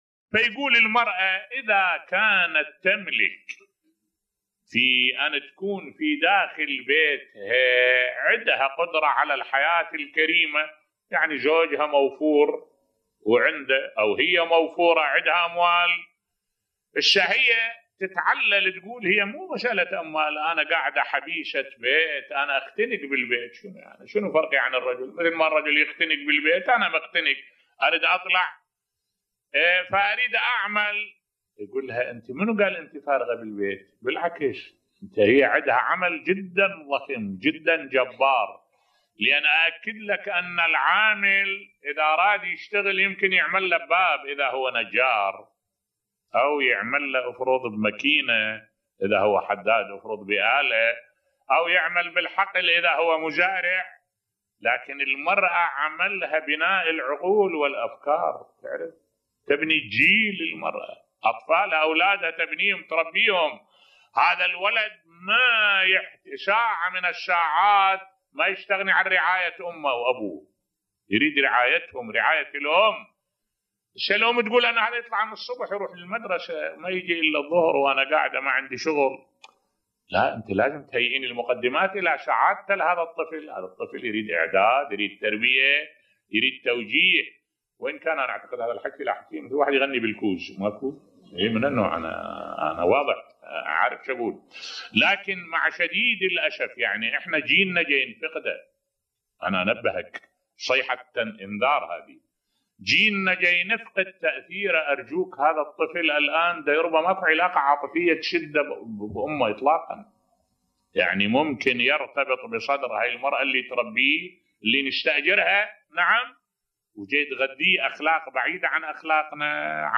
ملف صوتی أهمية عمل المرأة داخل بيتها بصوت الشيخ الدكتور أحمد الوائلي